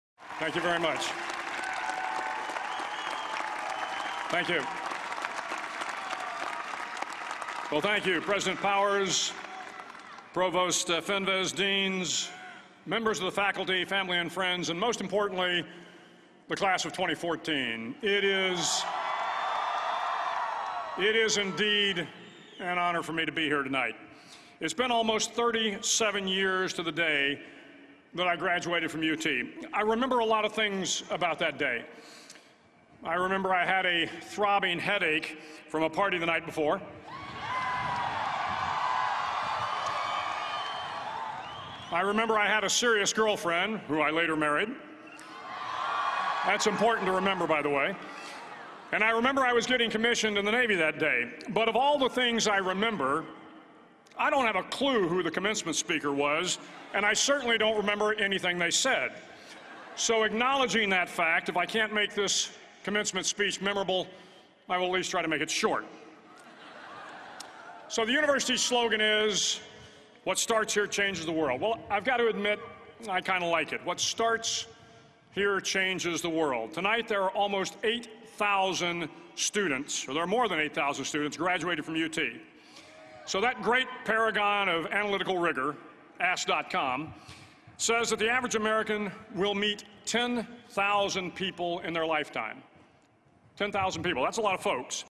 公众人物毕业演讲 第228期:威廉麦克雷文2014德州大学演讲(1) 听力文件下载—在线英语听力室